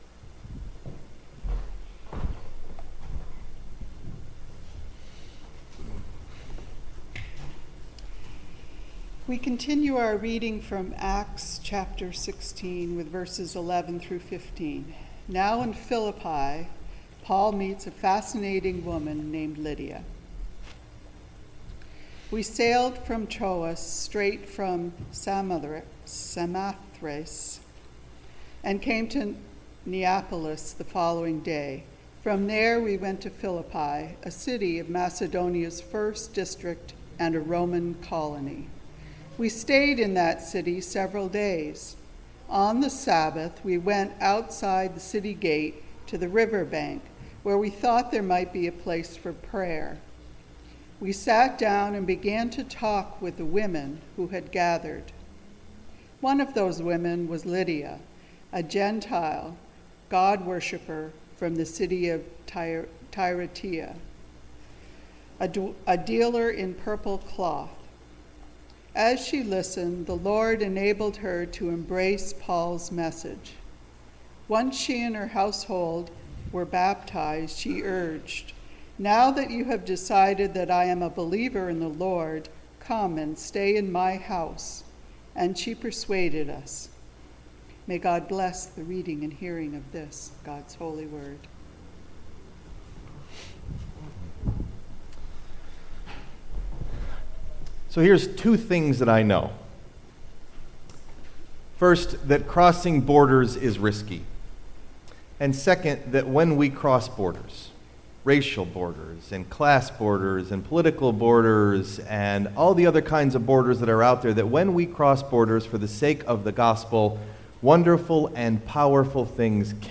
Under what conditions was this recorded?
Message Delivered at: The United Church of Underhill (UCC & UMC)